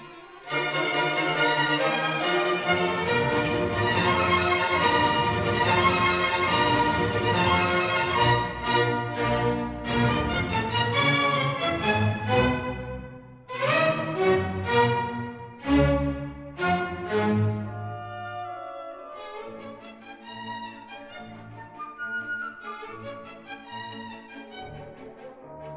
Recorded in the Sofensaal, Vienna on 28 May 1959.